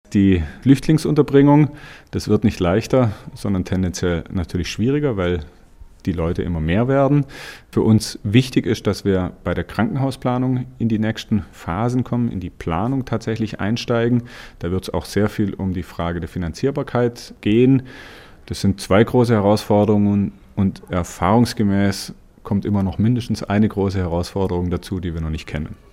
Zeno Danner (parteilos), Landrat Kreis Konstanz